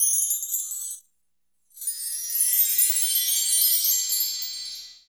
14 W.CHIME-L.wav